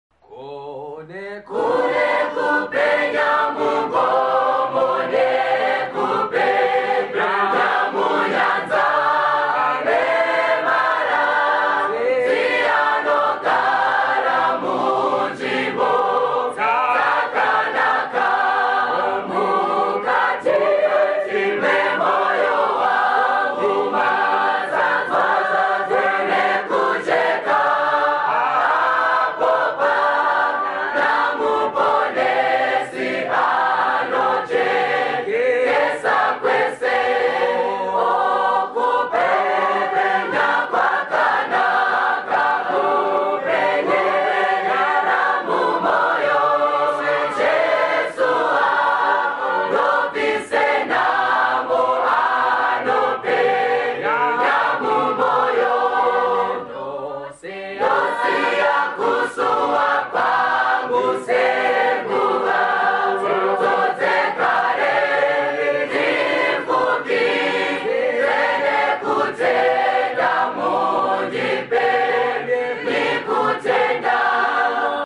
CIS Hymn 125 Kujeka sound effects free download
CIS Hymn 125 - Kujeka Mumwoyo by Ruya Adventist School Choir